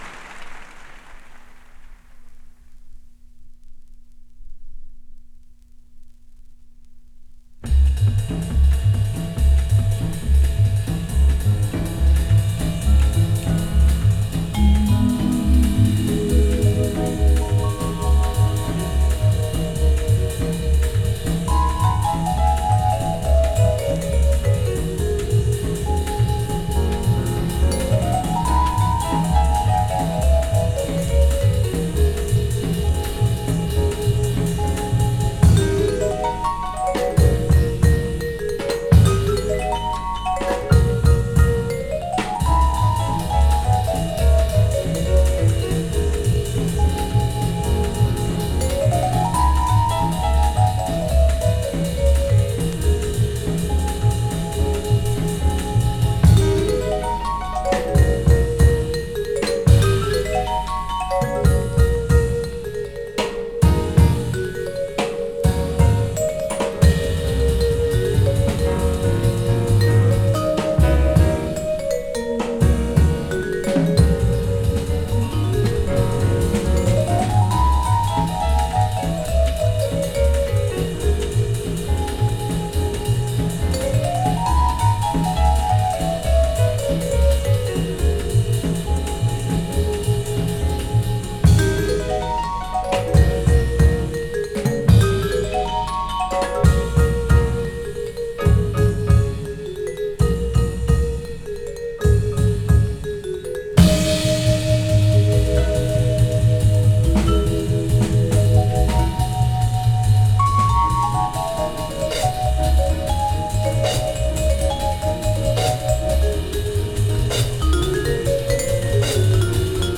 Vibes
Piano
Bass
Drums
This live album